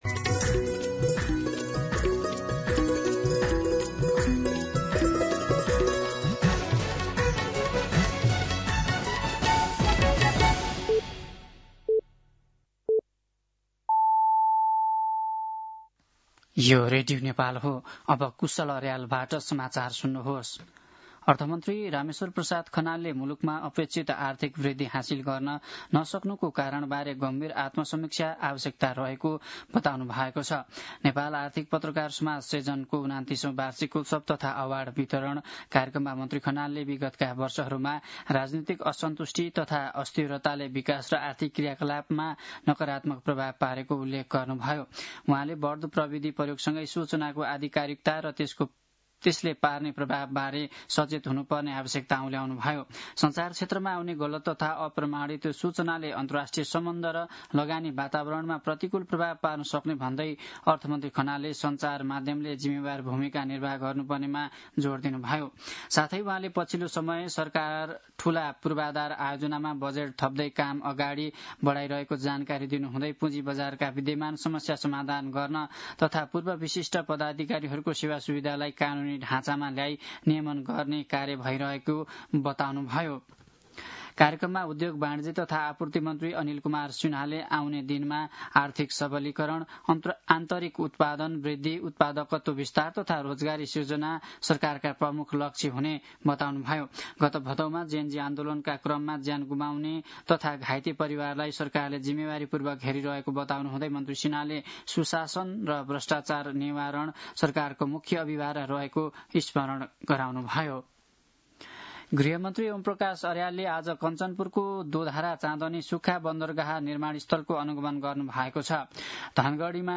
दिउँसो ४ बजेको नेपाली समाचार : १५ मंसिर , २०८२